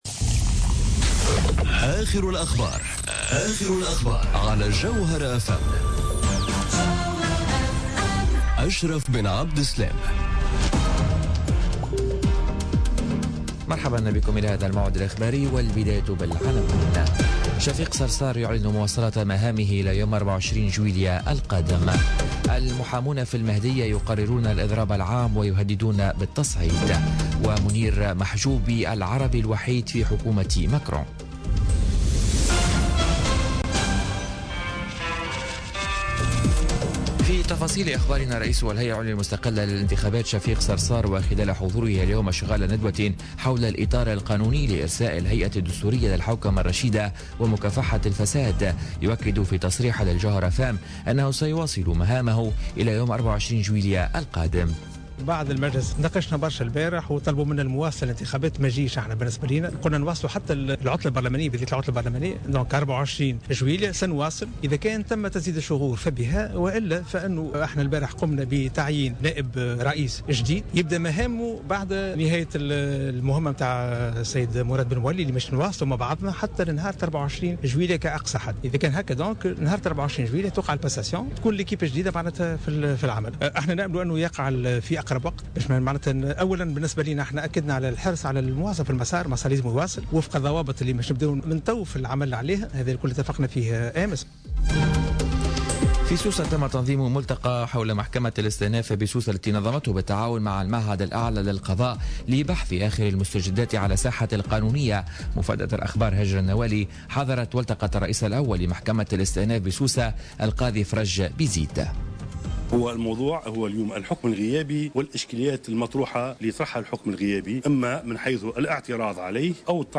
نشرة أخبار منتصف النهار ليوم الخميس 18 ماي 2017